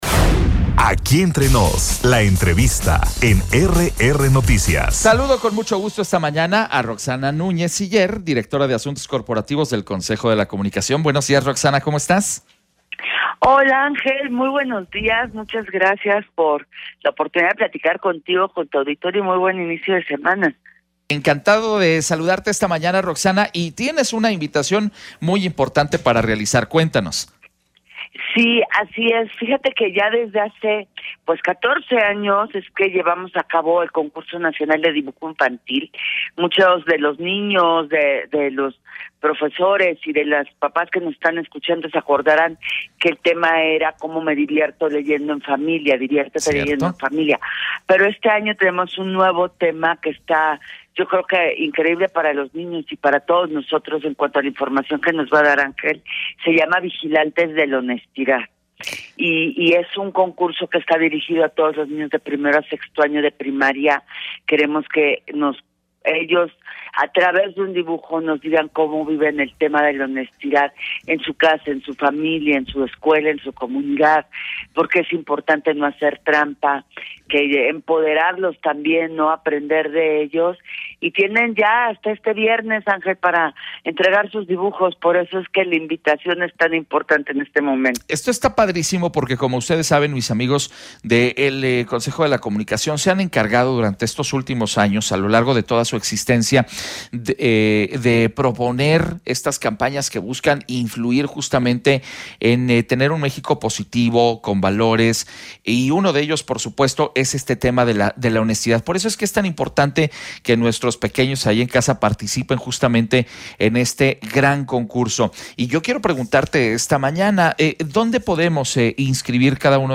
EntrevistasMultimediaNacionalesPodcast